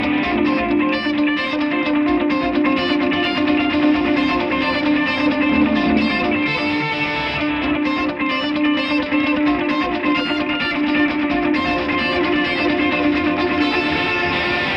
寒冷的吉他
标签： 130 bpm Rock Loops Guitar Electric Loops 2.48 MB wav Key : Unknown
声道立体声